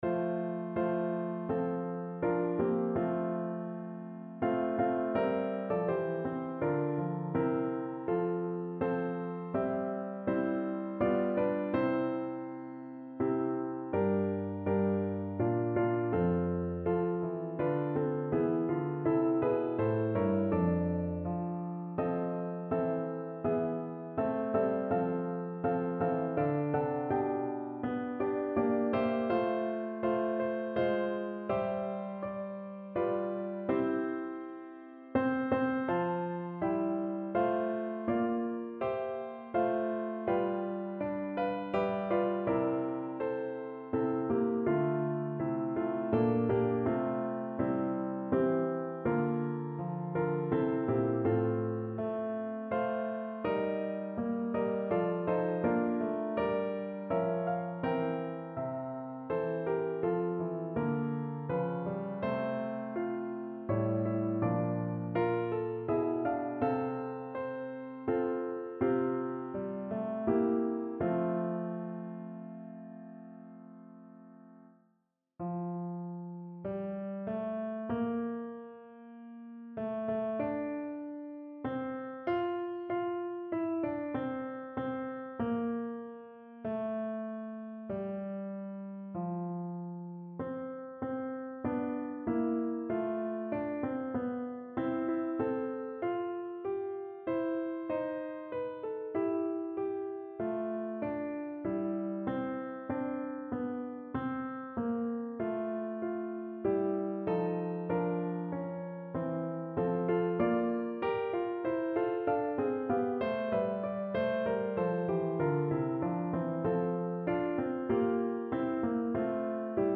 Motette (Psalm 40,1-4,12)
Notensatz (4 Stimmen gemischt)